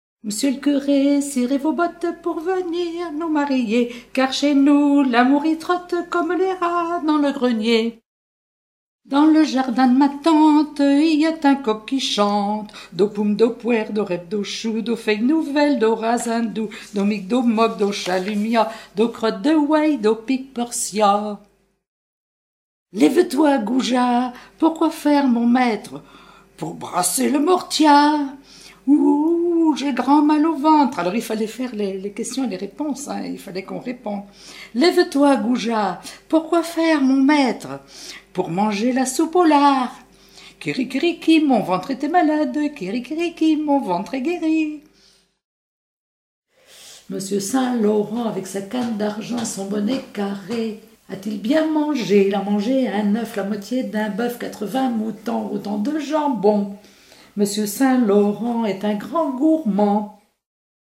Chants brefs et comptines